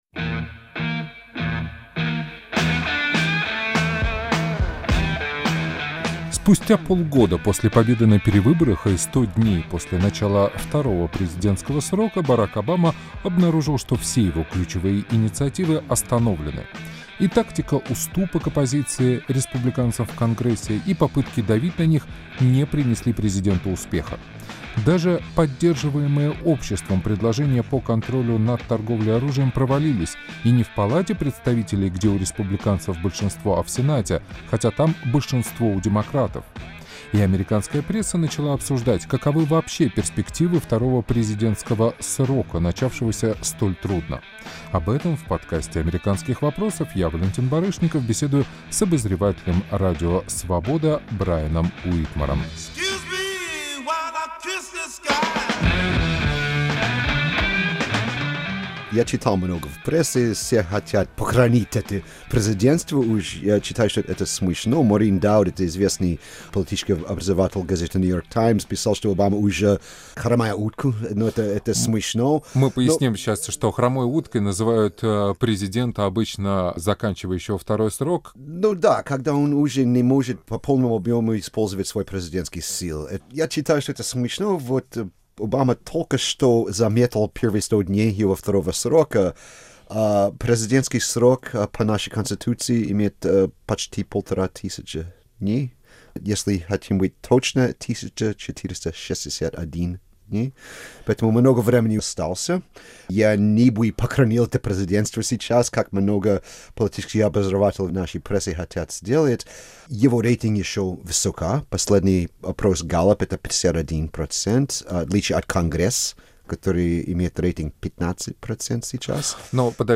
беседую обозреватели